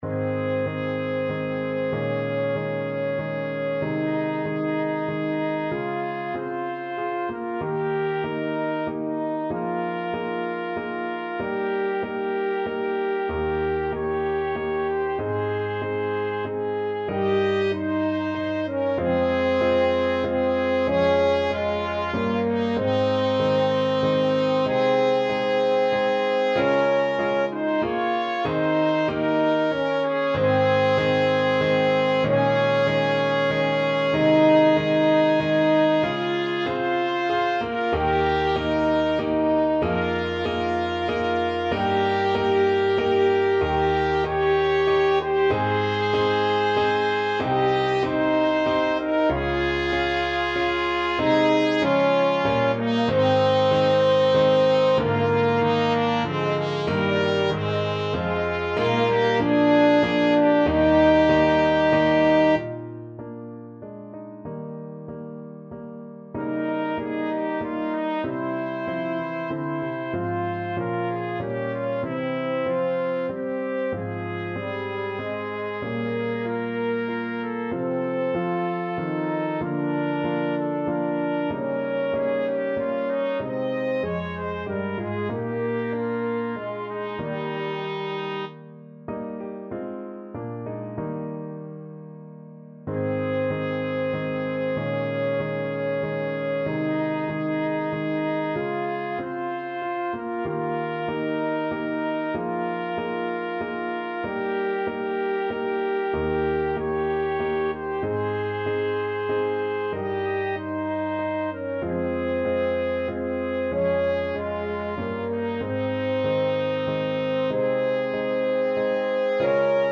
3/4 (View more 3/4 Music)
=95 Andante